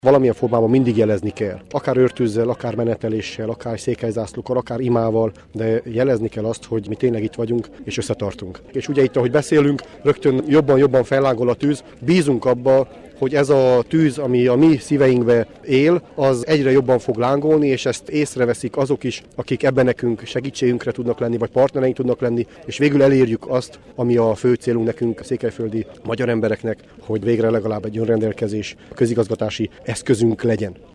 Az őrtüzek gyújtásának jelentőségéről Nyárádszereda polgármestere, Tóth Sándor így nyilatkozott: